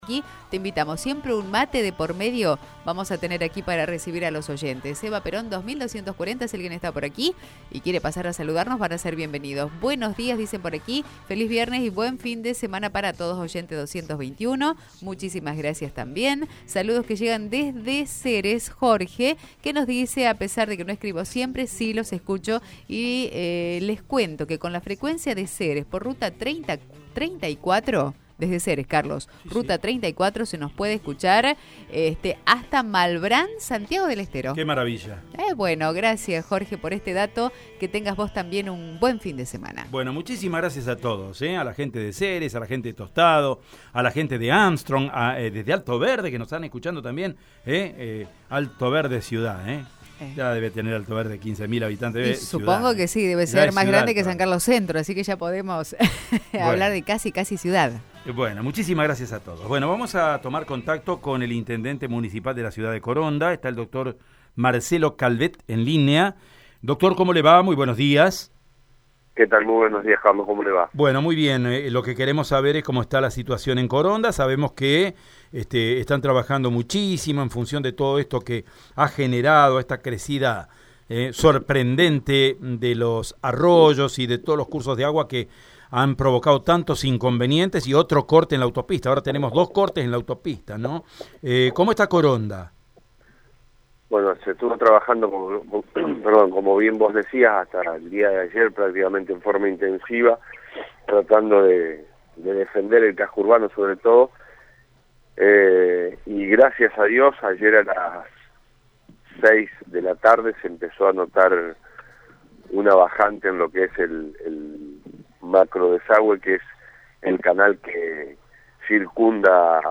Llovió en promedio unos 340 milímetros durante dos días en Coronda pese a ello el intendente de la ciudad, Marcelo Calvet, dio buenas noticias por Radio EME.
IntendenteHugo-Marcelo-Calvet.mp3